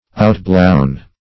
Outblown \Out"blown`\, a. Inflated with wind.